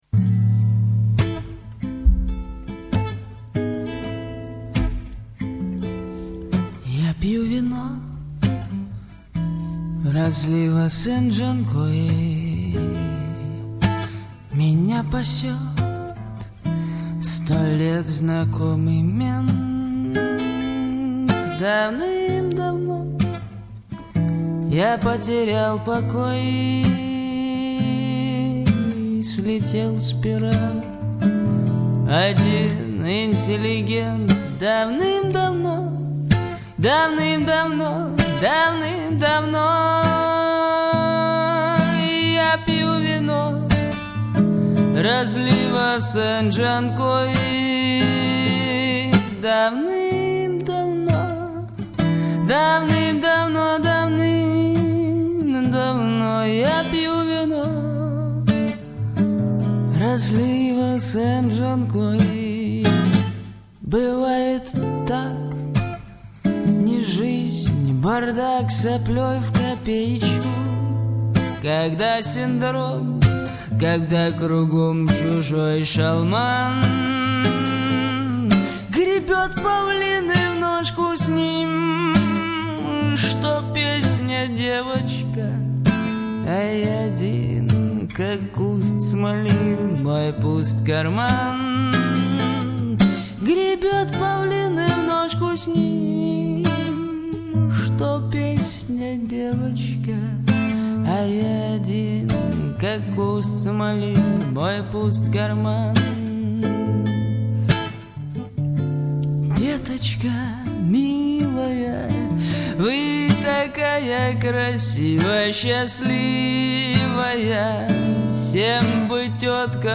обладающая уникальным, удивительно красивым альтом.
Ее бархатный, красивый альт всегда покорял слушателей.